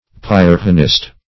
Pyrrhonist \Pyr"rho*nist\, n. A follower of Pyrrho; a skeptic.